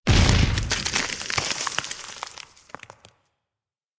destroy_block_debris.ogg